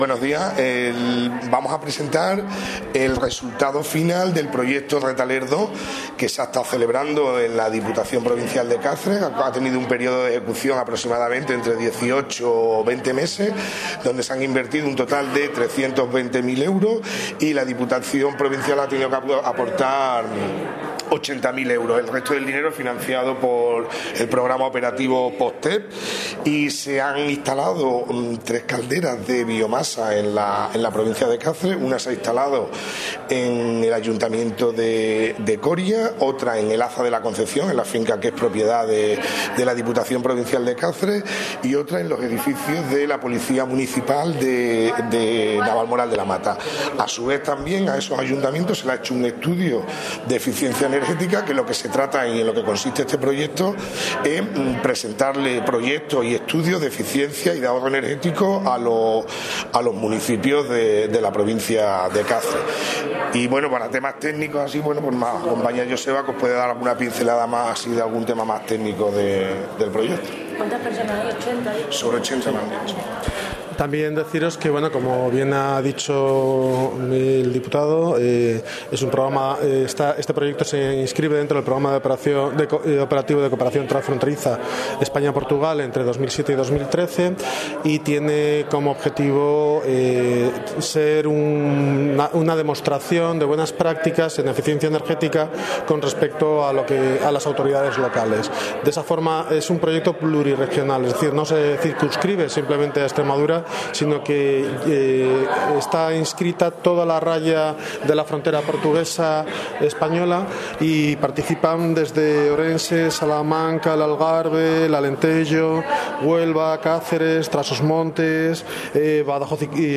CORTES DE VOZ
El diputado de Desarollo Local y Formación, Samuel Fernández Macarro, ha inaugurado este jueves en el Complejo Cultural San Francisco de Cáceres la Jornada Final del proyecto RETALER II: ‘Eficiencia energética en los municipios’, financiado con fondos FEDER.